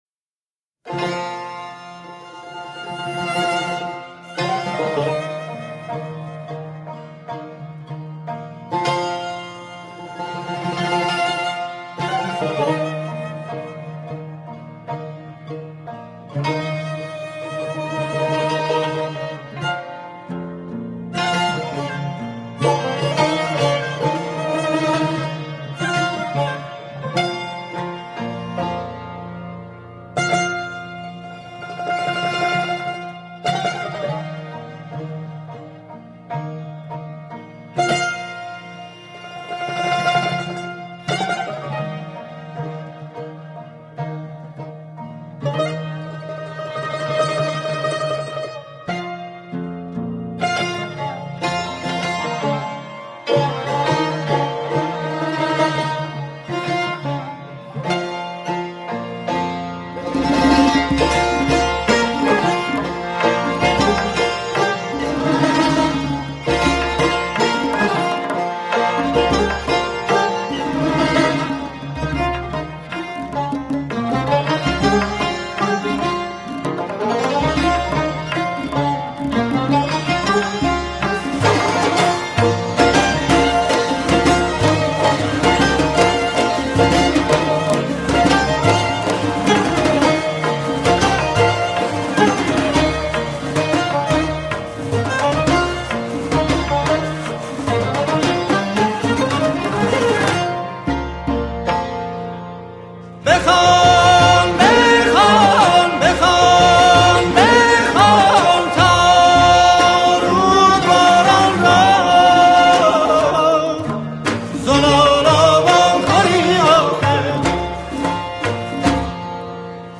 کمانچه
سازهای کوبه ای
سنتور
تار
تنبک